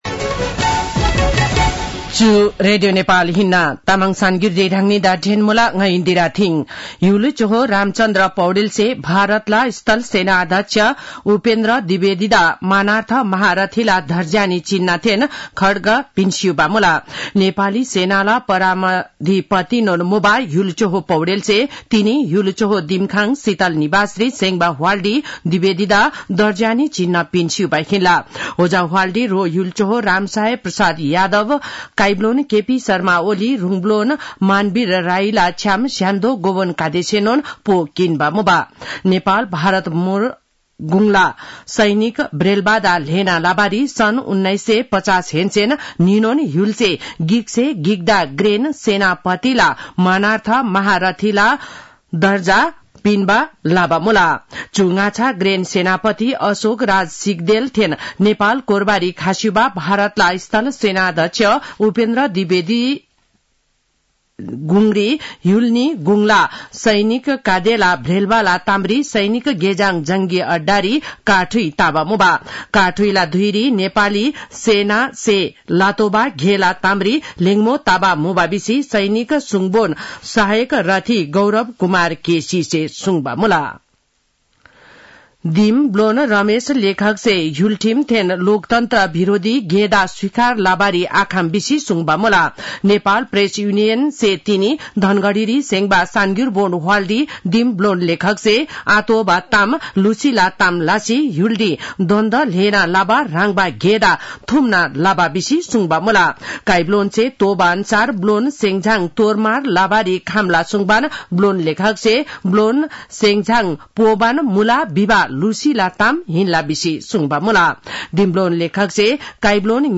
तामाङ भाषाको समाचार : ७ मंसिर , २०८१
Tamang-news-8-6.mp3